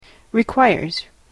/riˈkwaɪɝz(米国英語), ri:ˈkwaɪɜ:z(英国英語)/